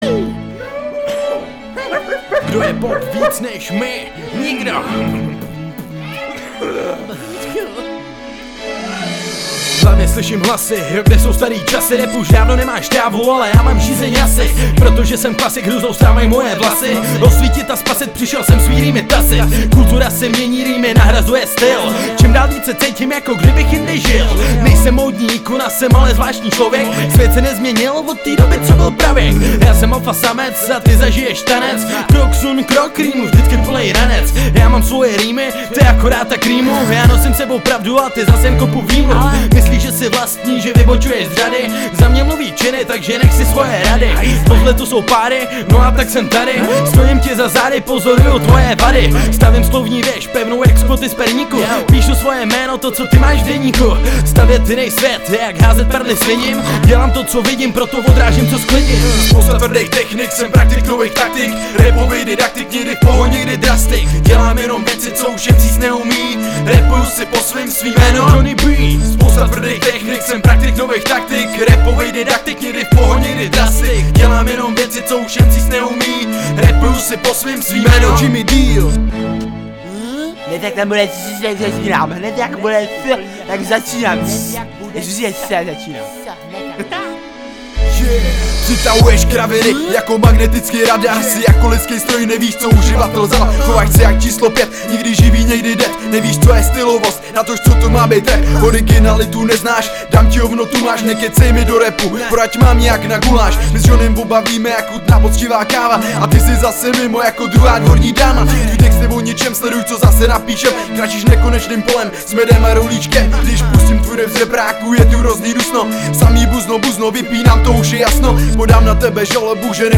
4 Styl: Hip-Hop Rok